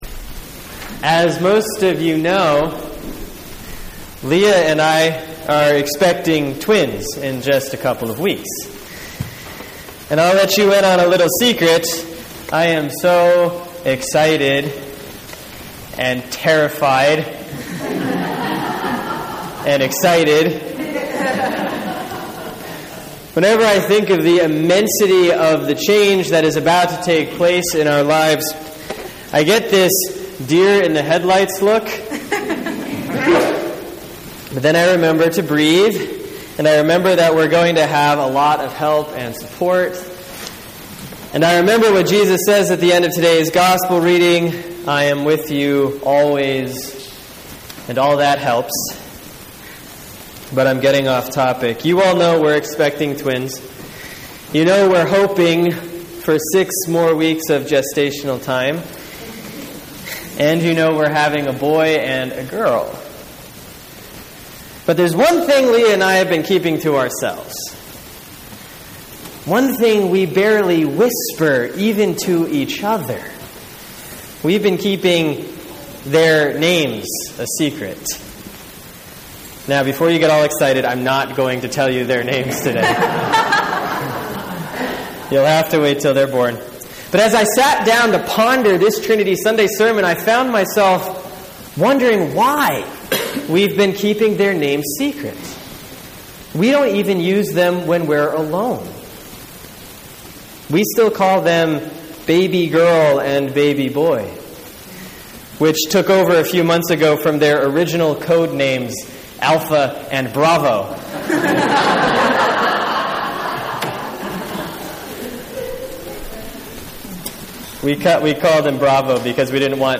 Sermon for Sunday, June 15, 2014 || Trinity Sunday, Year A || Genesis 1:1 – 2:4a